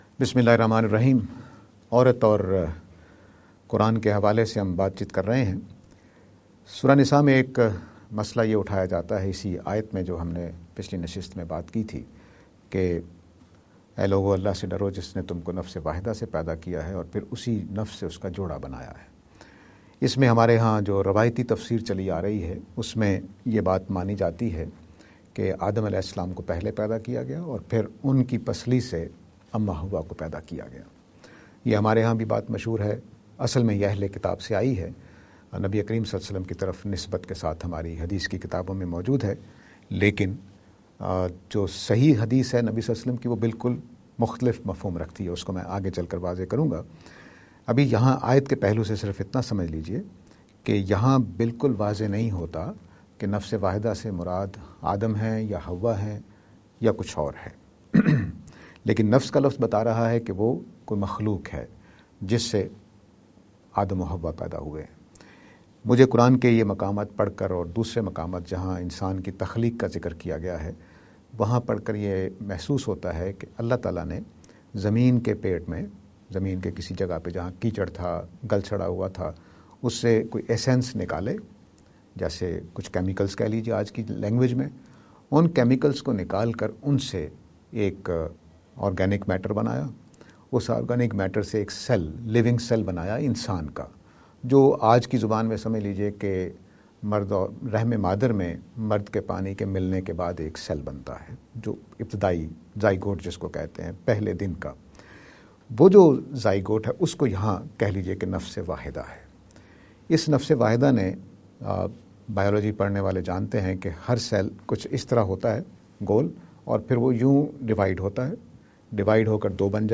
lecture series on "Women and Islam".